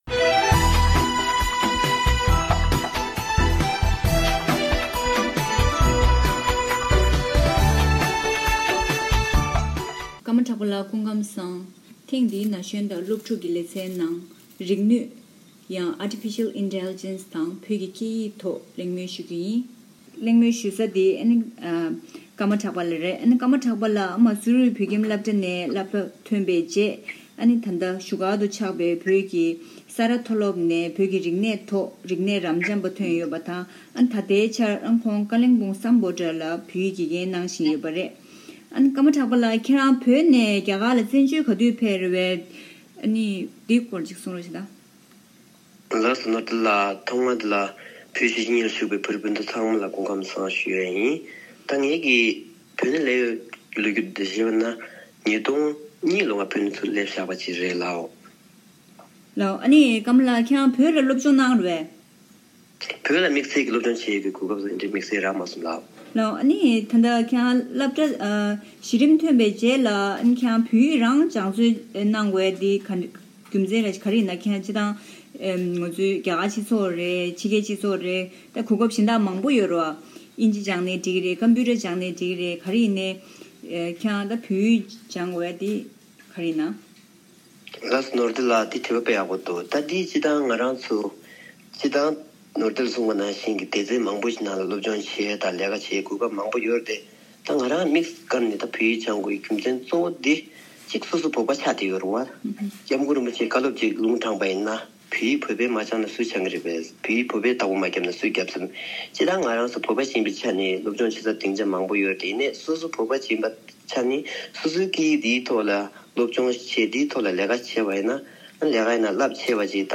གླེང་མོལ་ཞུས་པ་གསན་རོགས་གནང་།